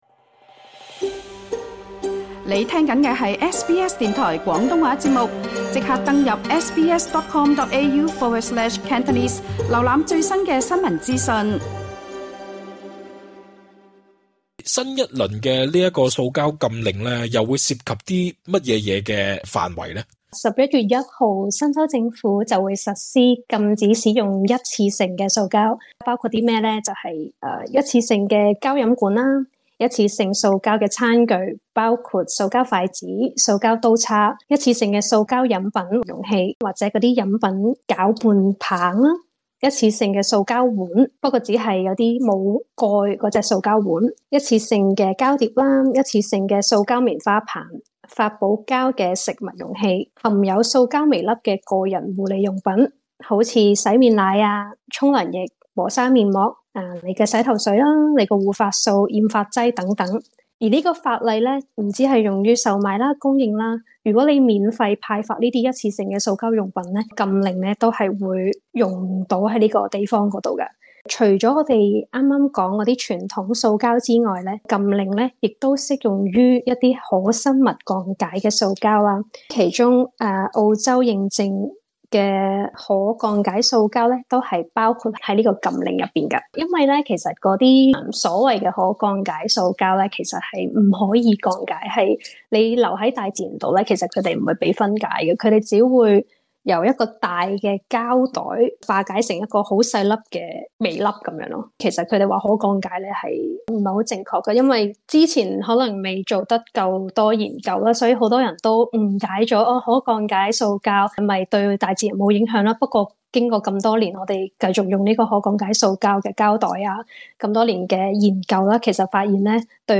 社區訪問